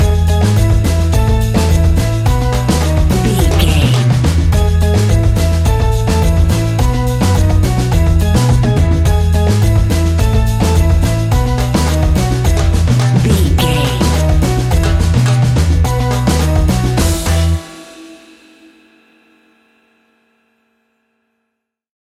That perfect carribean calypso sound!
Ionian/Major
calypso
steelpan
drums
percussion
bass
brass
guitar